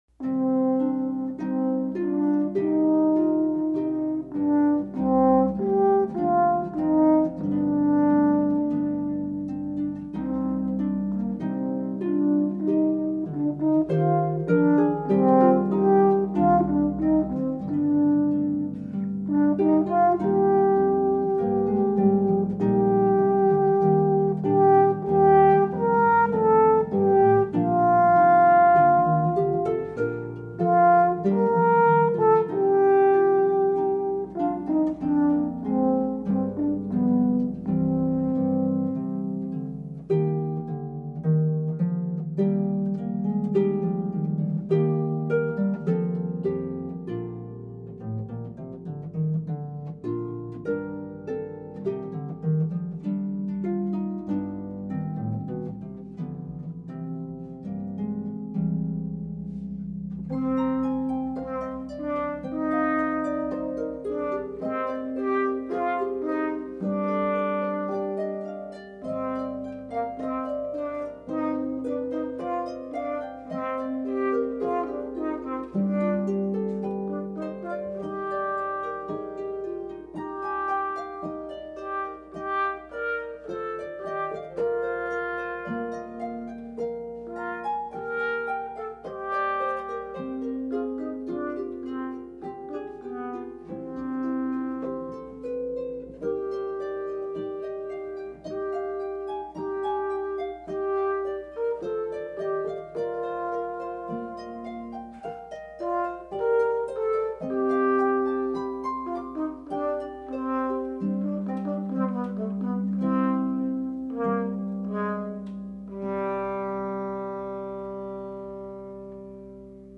for harp and horn